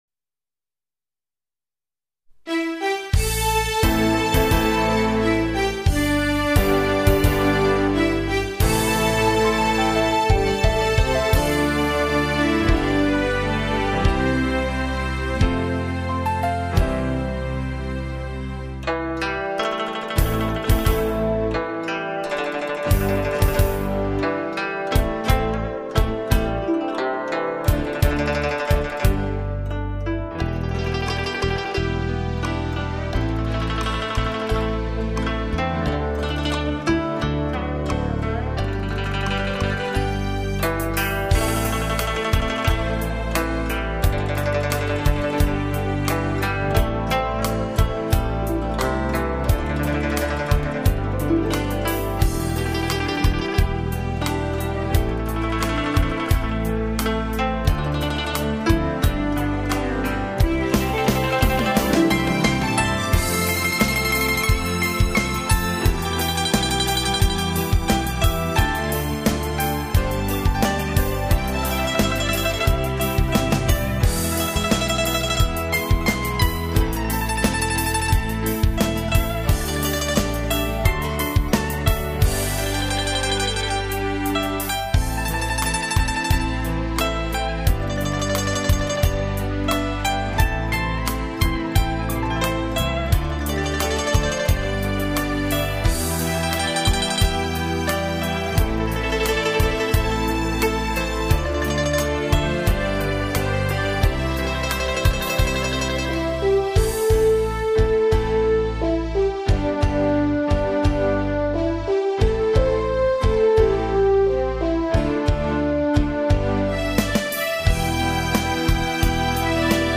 古筝独奏